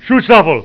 Sight